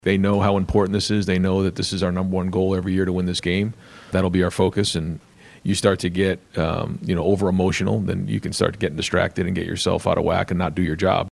Ohio State travels to Ann Arbor tomorrow to face Michigan. Ohio State coach Ryan Day says his team understands what's on the line.